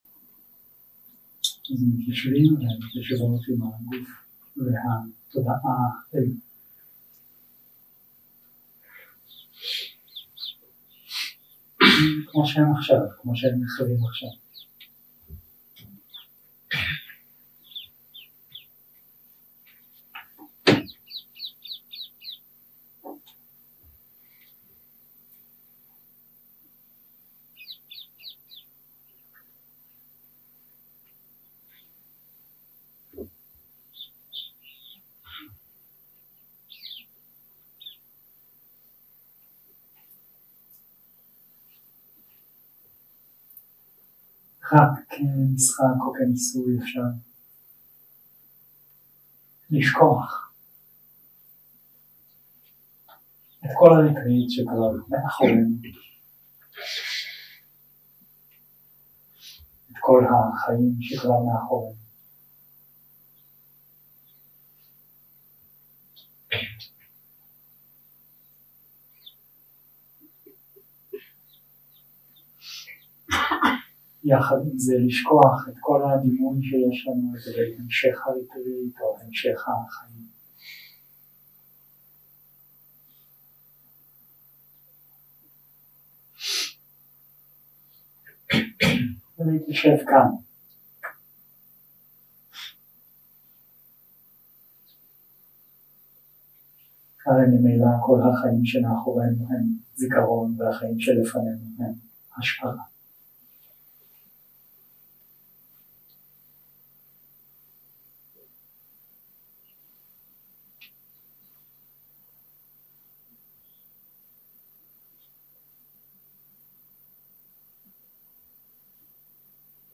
יום 3 – הקלטה 6 – צהריים – מדיטציה מונחית
Dharma type: Guided meditation